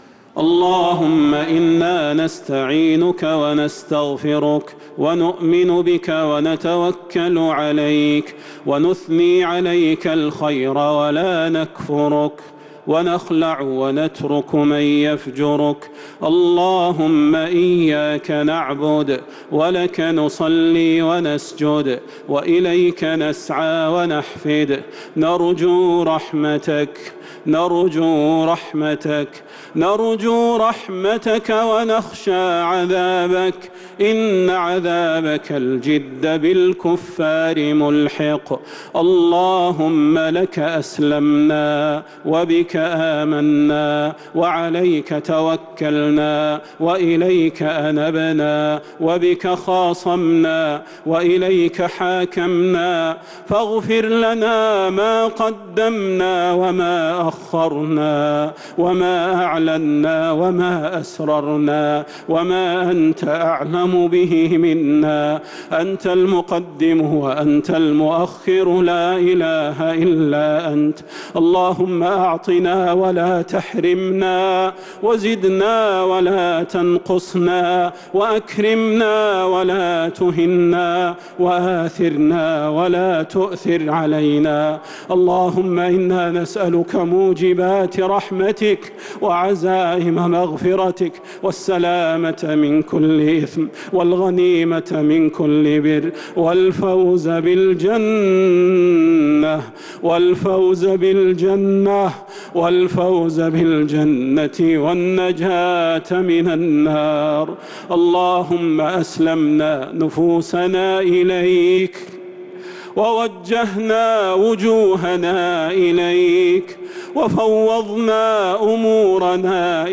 دعاء القنوت ليلة 16 رمضان 1443هـ | Dua for the night of 16 Ramadan 1443H > تراويح الحرم النبوي عام 1443 🕌 > التراويح - تلاوات الحرمين